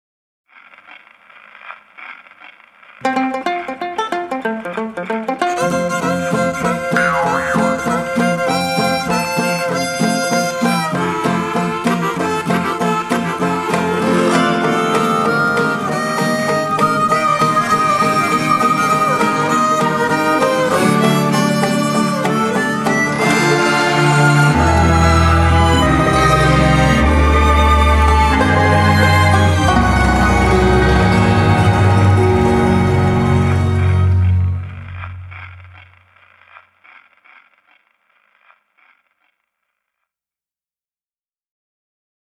Original Film Score
surreal film score